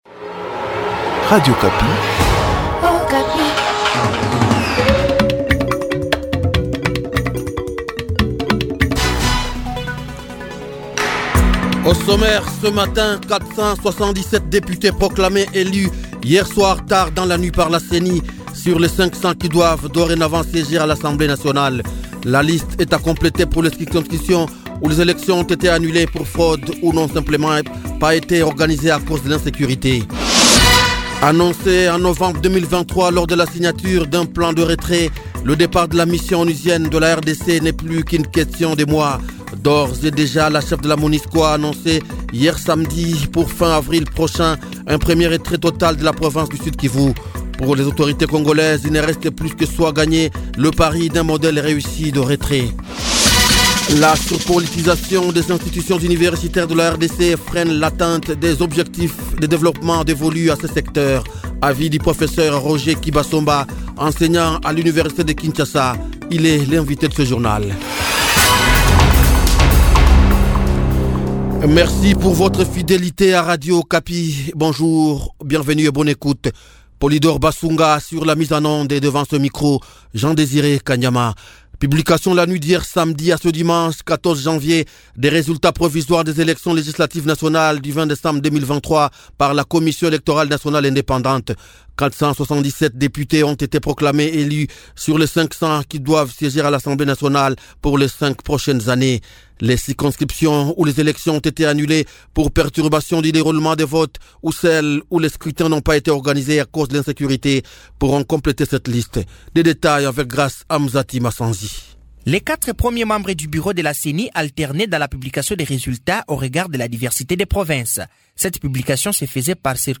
Journal Matin
CONDUCTEUR JOURNAL MIDI DIMANCHE 14 JANVIER 2024